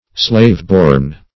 Slaveborn \Slave"born`\, a. Born in slavery.